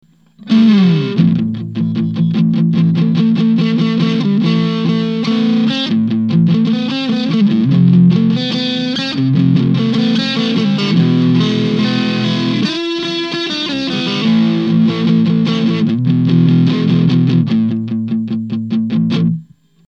single neck
TS808_single_neck.mp3